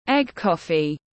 Cà phê trứng tiếng anh gọi là egg coffee, phiên âm tiếng anh đọc là /eɡ ˈkɒf.i/
Egg coffee /eɡ ˈkɒf.i/